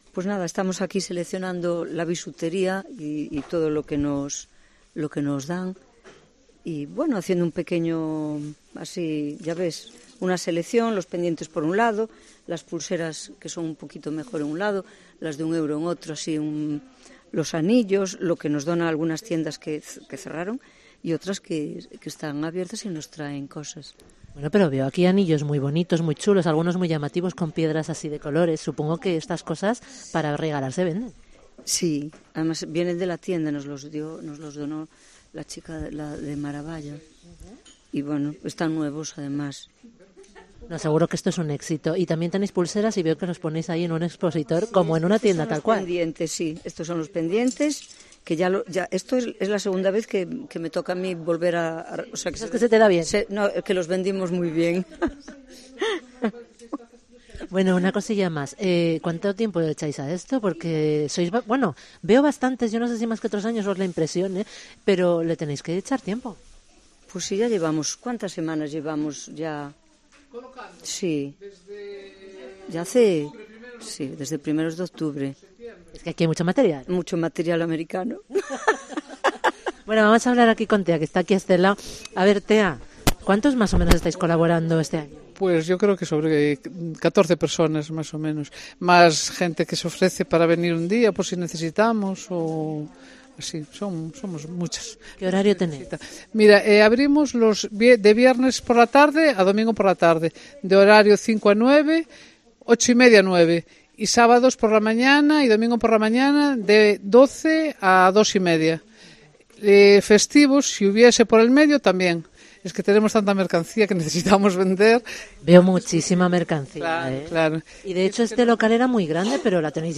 REPORTAJE en el Mercadillo del Cáncer de Ribadeo